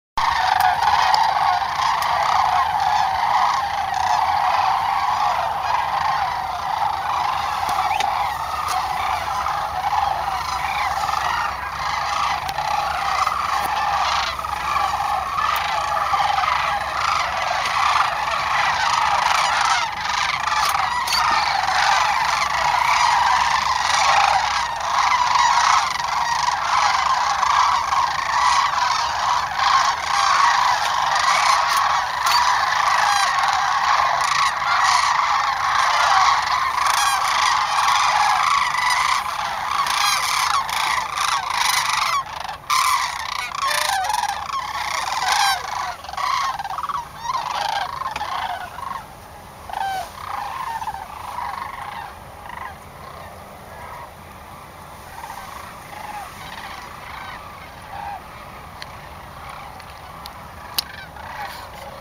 Sandhill cranes in flight
They can be noisy birds with distinct calls — rattling bugling type sounds that can be heard up to a mile away.